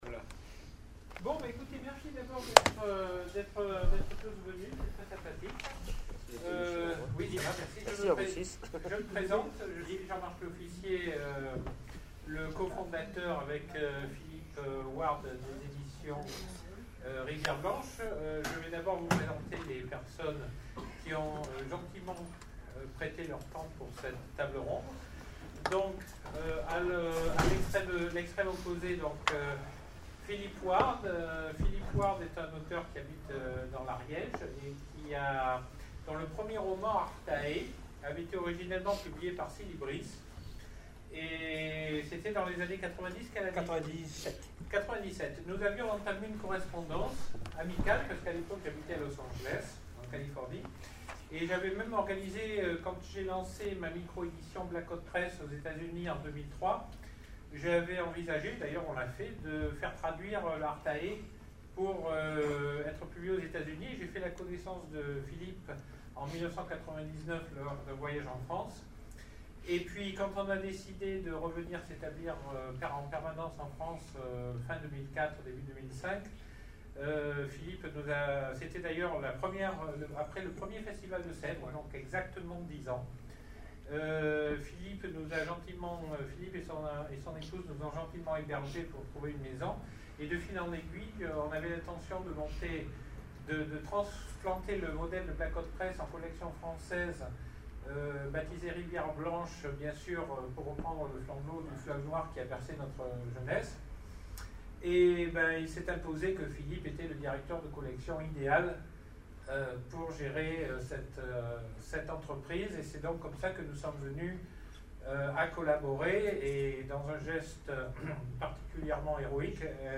Sèvres 2014 : Conférence Les 10 ans de Rivière Blanche